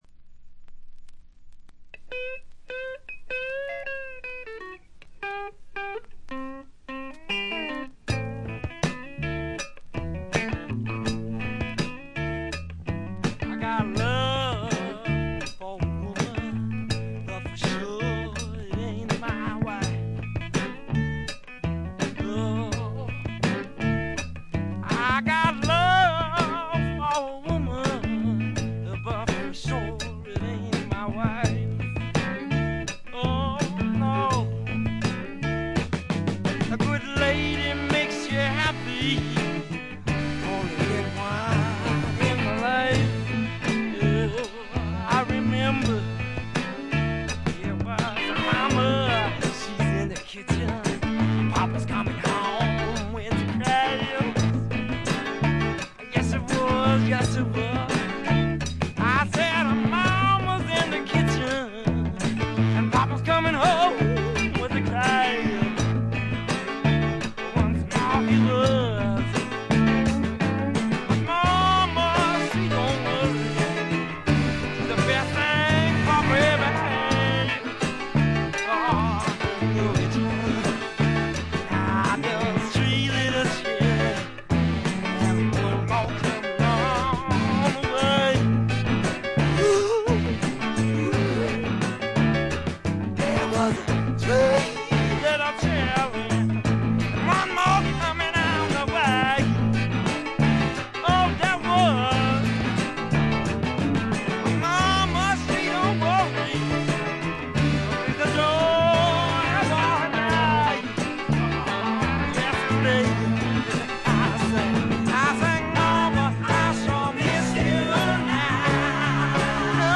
軽微なチリプチ少々、散発的なプツ音が少し。
まさしくスワンプロックの理想郷ですね。
試聴曲は現品からの取り込み音源です。
Vocals, Acoustic Guitar, Piano, Violin